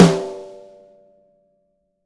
ST DRYSN1.wav